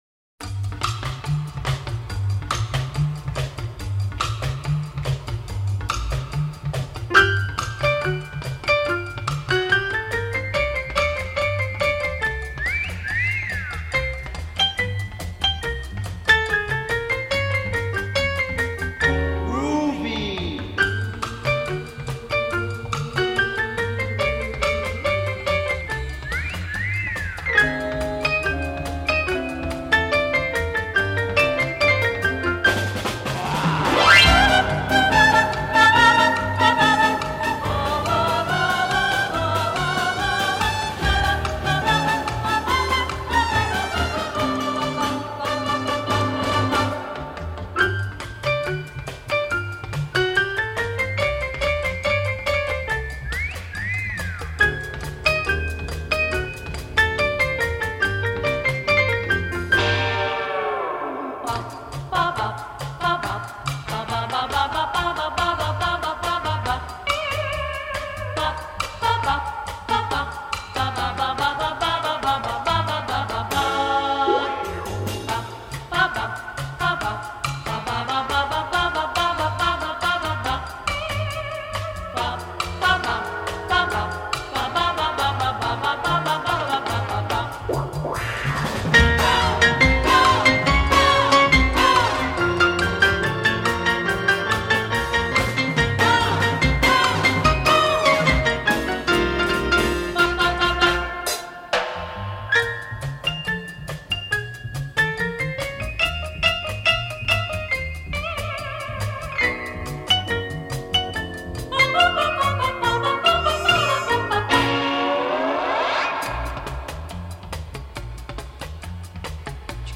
little jazzy number